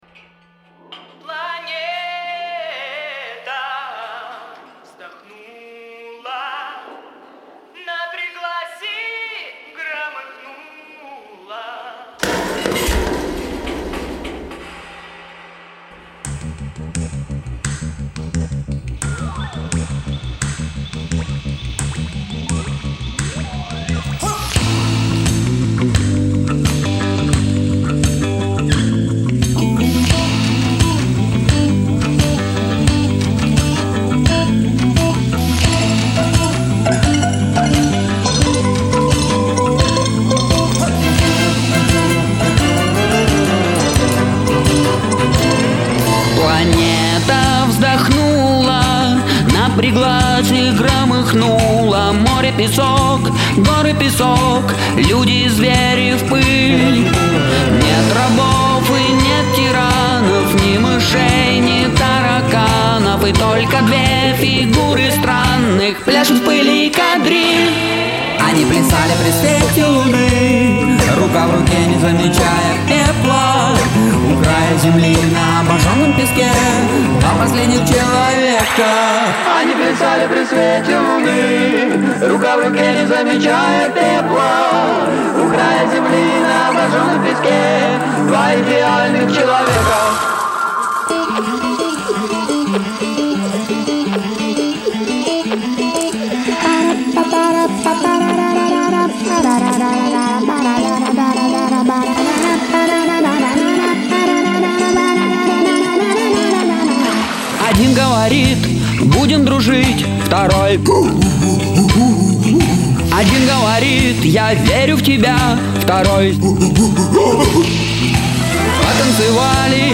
Новый альбом минского спецпоп-трио